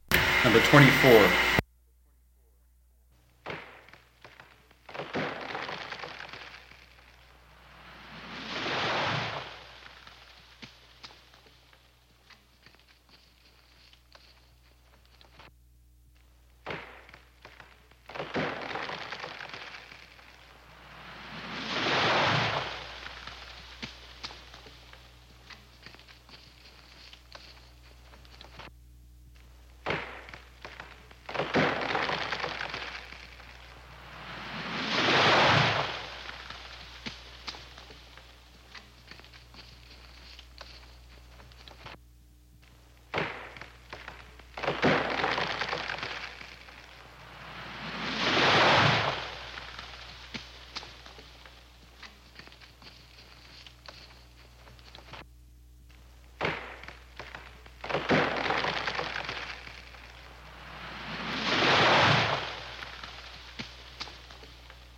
古老的坠机事件 " G2624树的坠机事件
描述：大而重的树木开裂，分裂和落下，叶子嗖嗖地撞击地面。 这些是20世纪30年代和20世纪30年代原始硝酸盐光学好莱坞声音效果的高质量副本。 40年代，在20世纪70年代早期转移到全轨磁带。我已将它们数字化以便保存，但它们尚未恢复并且有一些噪音。